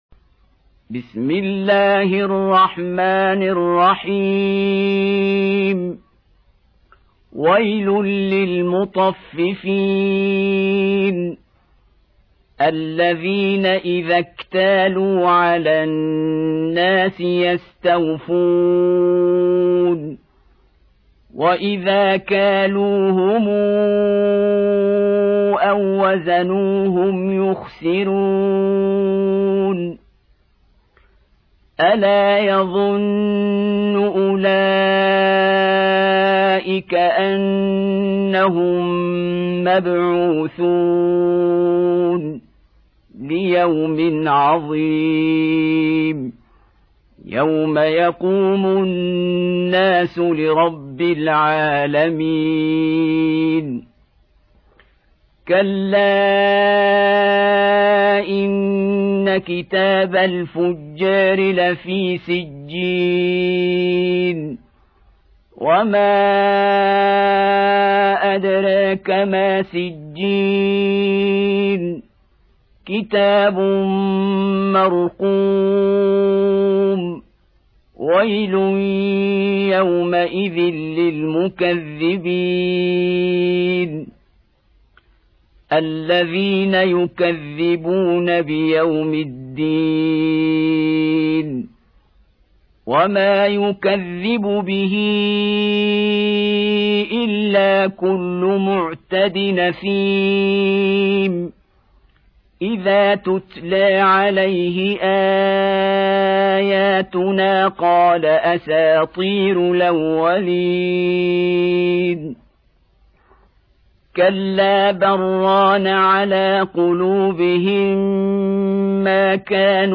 Audio Quran Tarteel Recitation
Surah Repeating تكرار السورة Download Surah حمّل السورة Reciting Murattalah Audio for 83. Surah Al-Mutaffif�n سورة المطفّفين N.B *Surah Includes Al-Basmalah Reciters Sequents تتابع التلاوات Reciters Repeats تكرار التلاوات